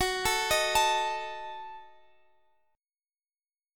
Listen to Gbm6 strummed